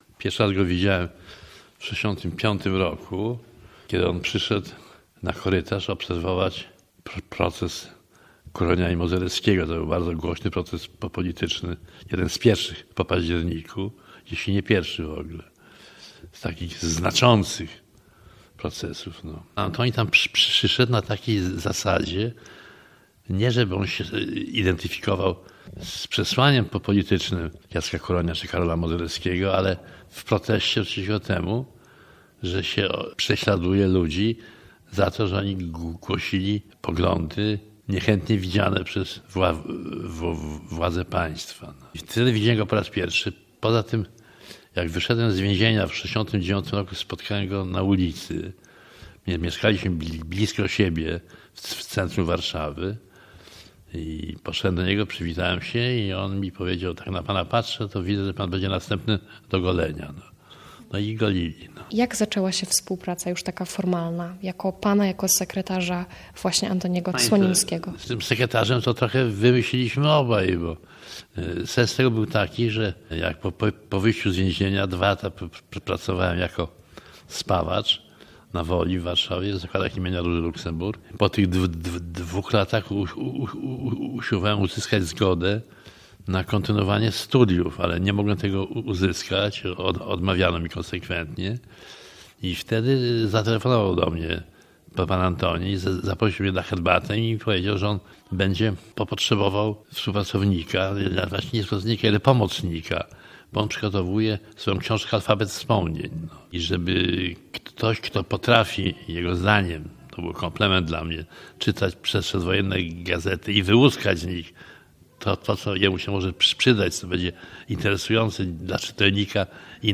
Publicysta, historyk i polityk Adam Michnik uczestniczył w panelu dyskusyjnym o patronie 2025 roku - Antonim Słonimskim. Michnik był sekretarzem poety na początku lat 70. XX wieku.
Spotkanie odbyło się w ramach czwartku literackiego w Pałacu Działyńskich.